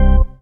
ORGAN-18.wav